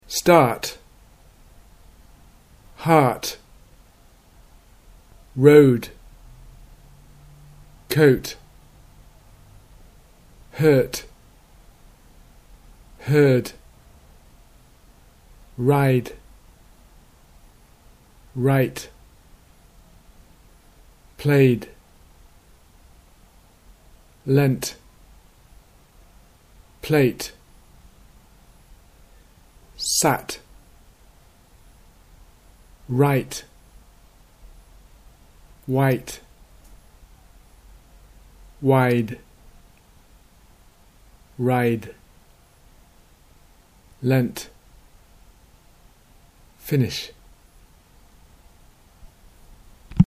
hard v heart - final devoicing
minimal pair discrimination